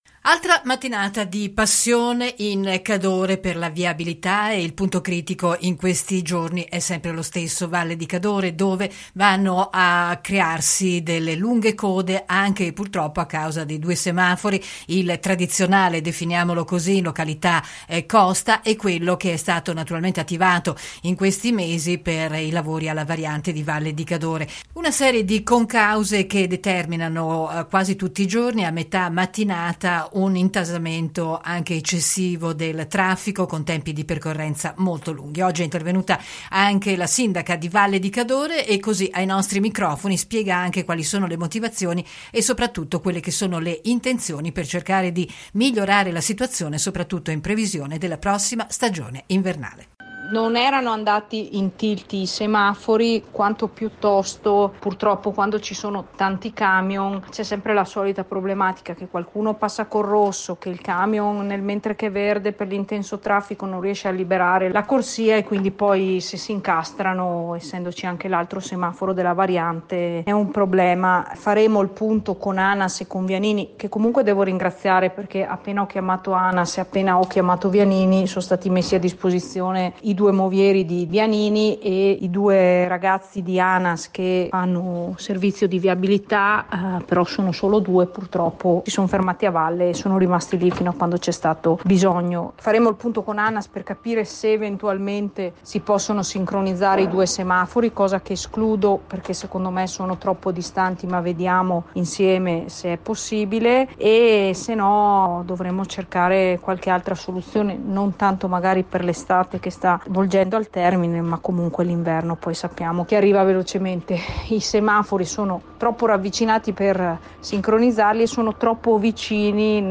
intervistata la sindaca di Valle di Cadore sui problemi della viabilità che si creano tutte le mattine con code e forti rallentamenti in Cadore da e per Cortina.